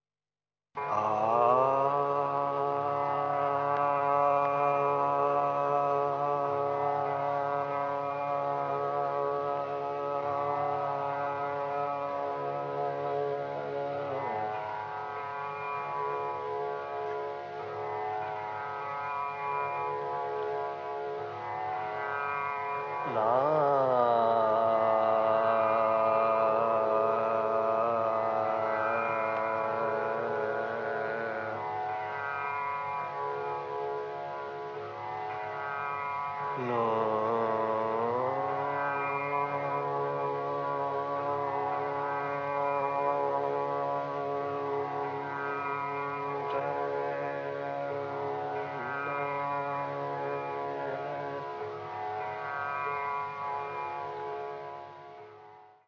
Meditation song